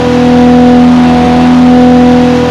supra_mid.wav